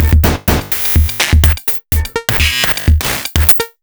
Metal Edge 02.wav